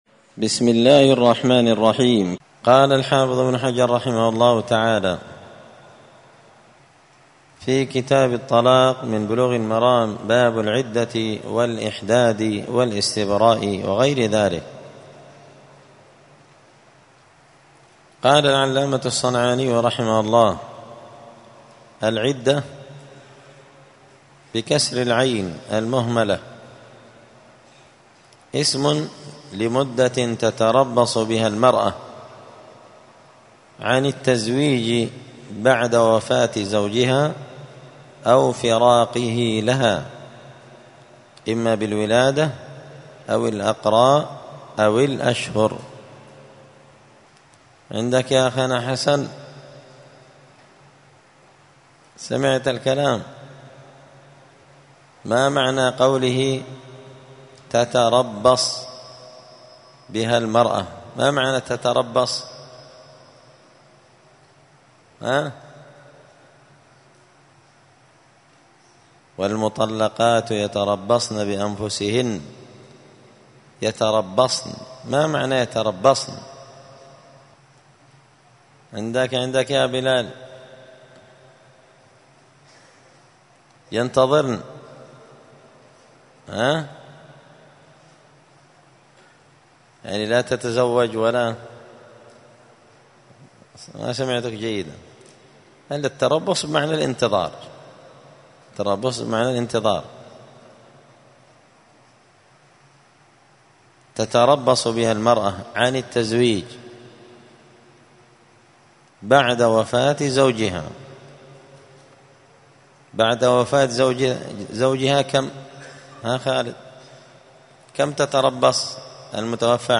*الدرس الثامن عشر (18) {تابع لباب العدة الإحداد والاستبراء}*